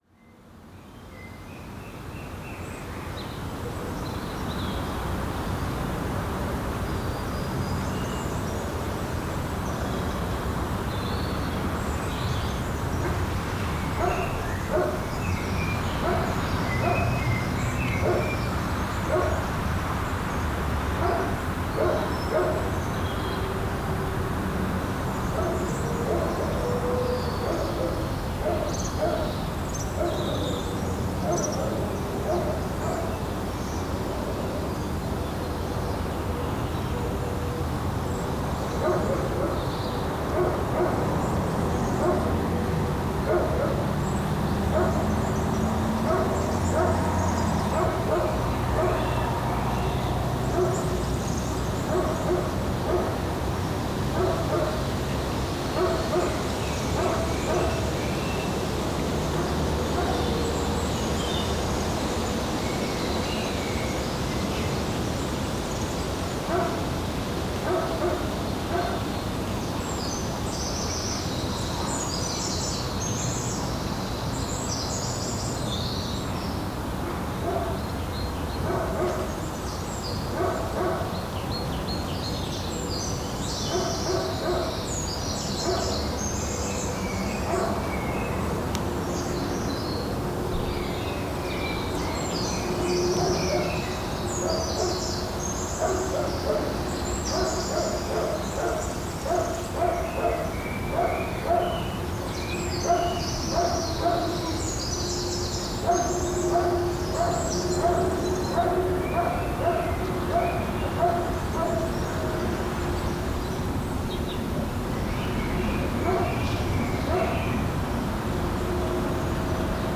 Paisagem sonora de vento sobre eucaliptos e pinheiros em baldio junto à Rua da Seara em Torredeita, Torredeita a 29 Março 2016.
NODAR.00541 – Boa Aldeia, Farminhão e Torredeita: Vento sobre eucaliptos e pinheiros em baldio junto à Rua da Seara em Torredeita